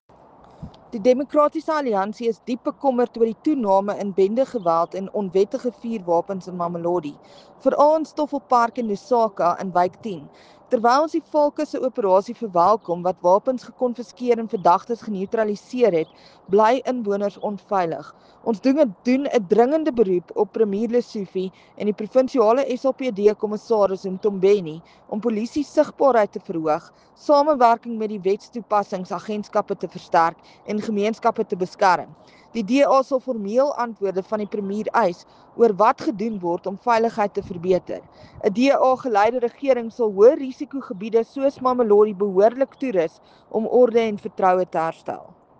Afrikaans soundbites by Crezane Bosch MPL.